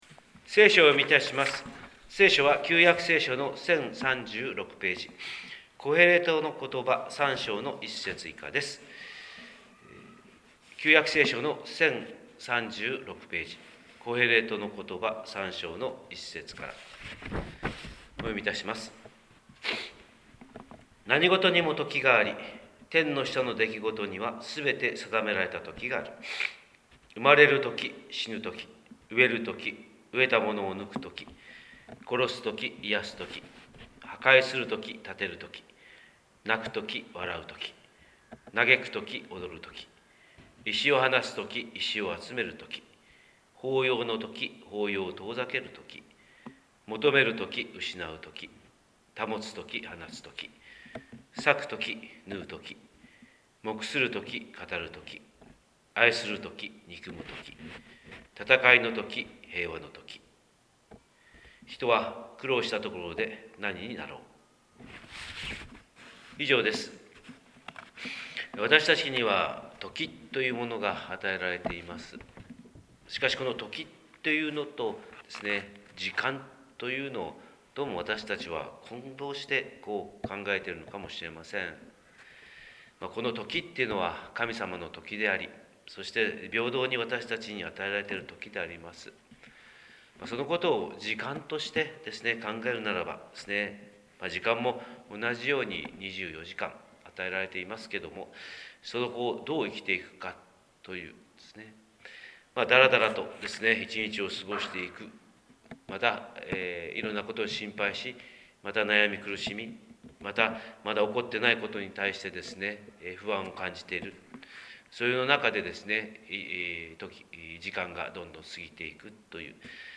神様の色鉛筆（音声説教）: 広島教会朝礼拝250310
広島教会朝礼拝250310「時と時間」